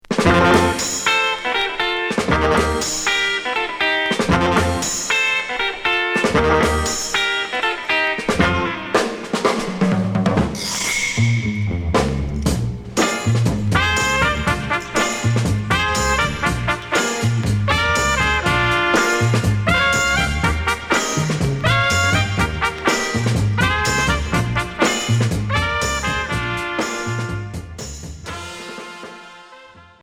Pop jerk